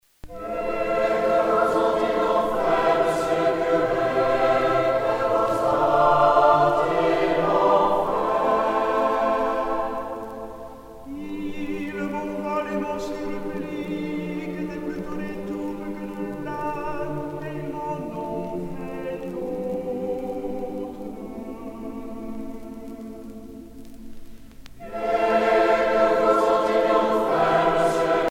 Franco-Allemande de Paris (chorale)
Pièce musicale éditée